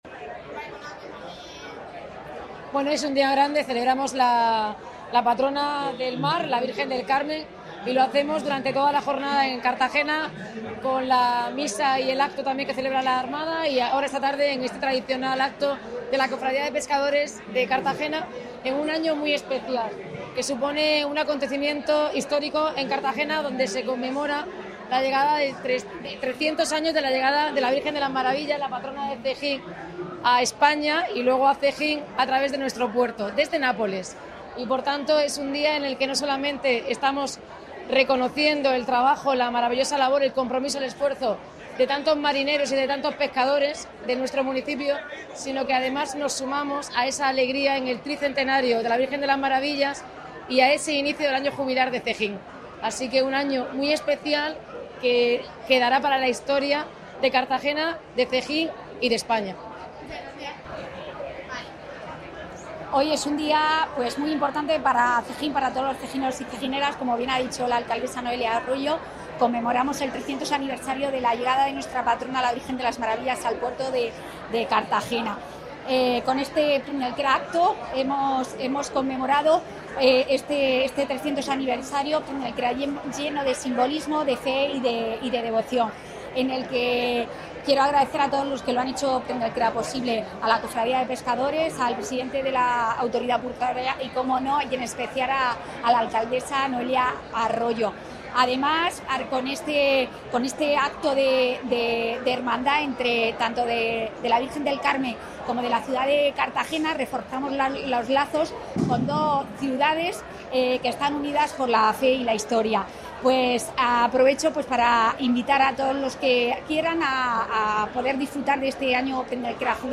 El Arsenal Militar acoge el tradicional acto castrense con la presencia de la alcaldesa Noelia Arroyo y los pescadores donan más de mil kilos de género a los necesitados
A lo largo del día se sucedieron los actos festivos en honor a la Virgen del Carmen en diferentes puntos del municipio, destacando las tradicionales procesiones marítimas y terrestres, en las que la imagen de la Virgen salió acompañada por cientos de fieles y embarcaciones.